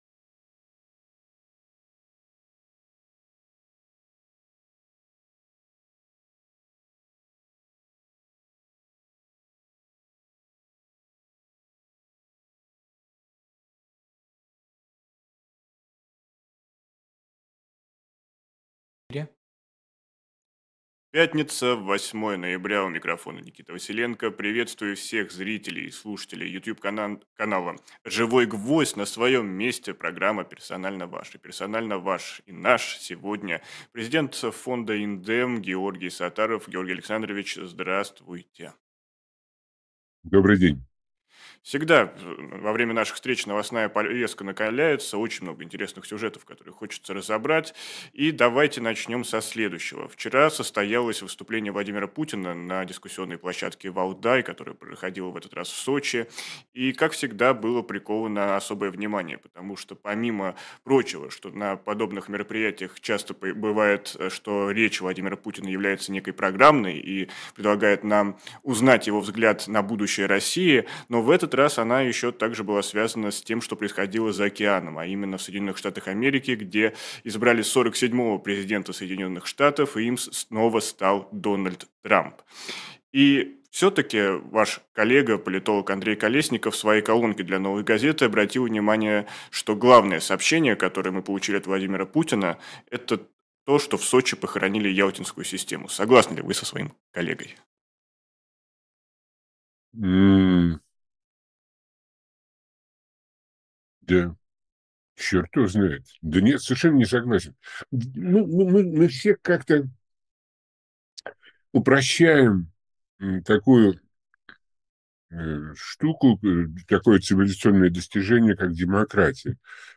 Георгий Сатаров политолог